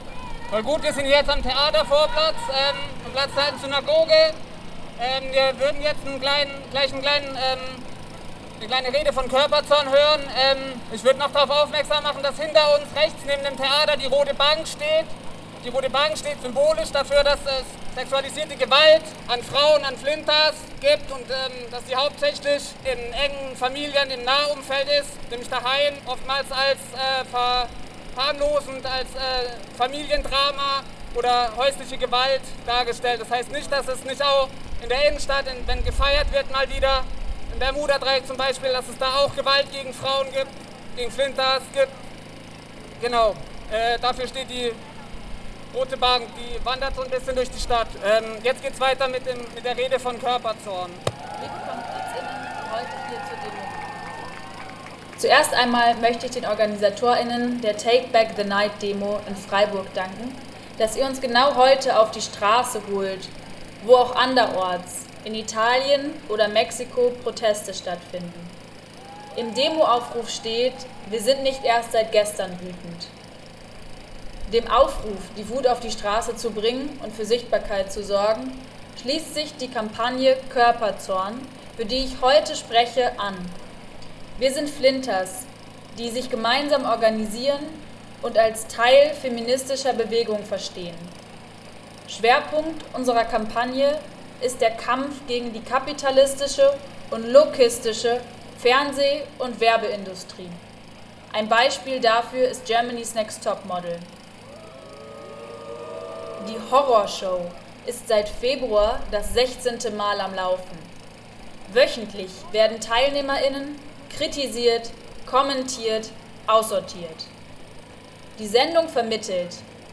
Auch ohne größeren öffentlichen Aufruf auf Social Media im Vorfeld kamen so mehrere dutzend Menschen zusammen und zogen über zwei Stunden unter lautstarkem Protest, zeitweise gestoppt durch die Polizei, gegen Patriarchat und Kapitalismus durch die Straßen.
Redebeiträge in voller Länge:
Rede Körperzorn (Platz der Alten Synagoge):